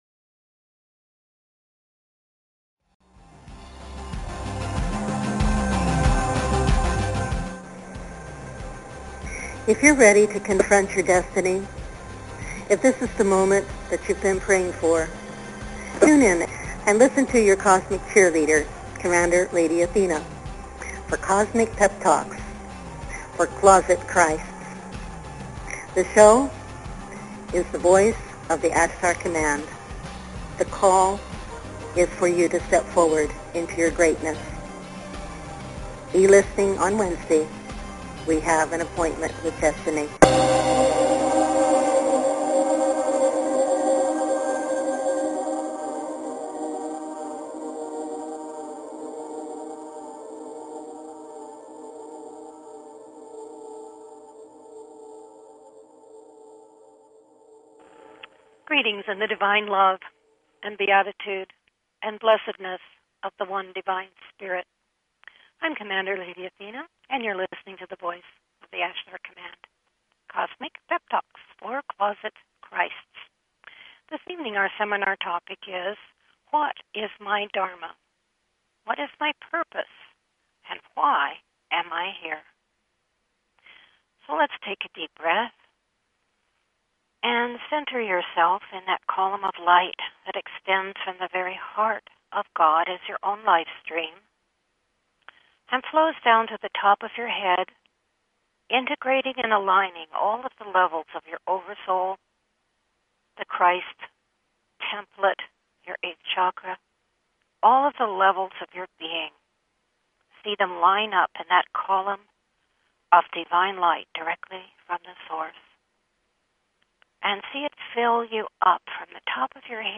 Talk Show Episode, Audio Podcast, The_Voice_of_the_Ashtar_Command and Courtesy of BBS Radio on , show guests , about , categorized as
Various experiential processes, meditations and teachings evoke your Divine knowing and Identity, drawing you into deeper com